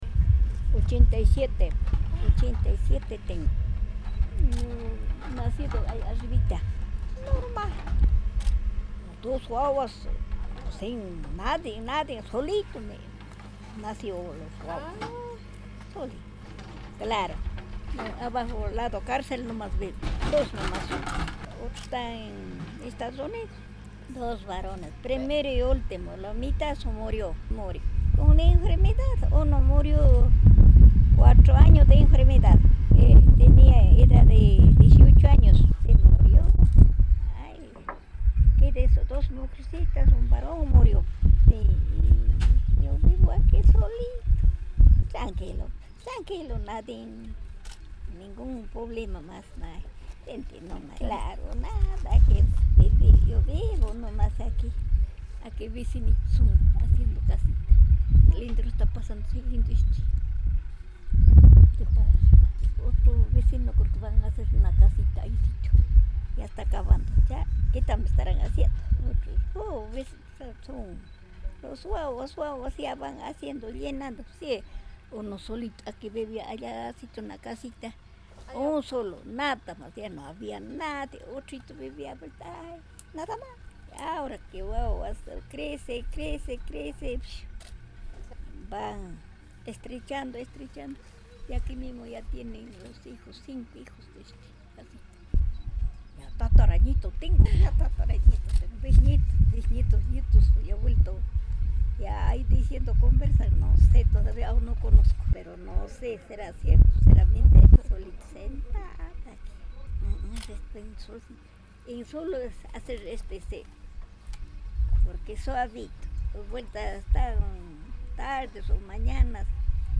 Vivo en Raymi